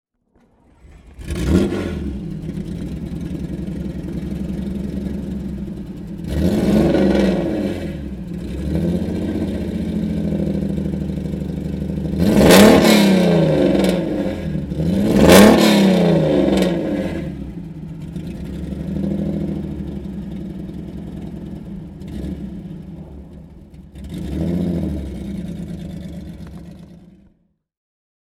Lamborghini Espada - superlative Gran Turismo with a powerful sound (Vehicle Articles)
Lamborghini_Espada_1970_aussen.mp3